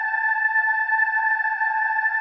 WAIL PAD 8.wav